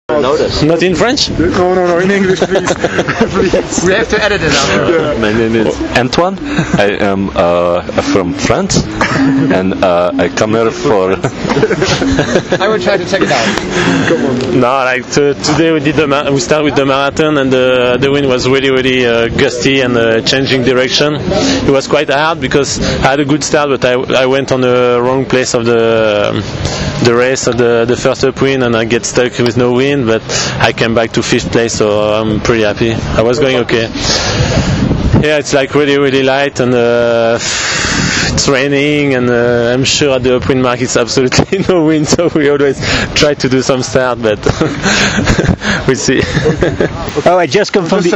More interviews… – Formula Windsurfing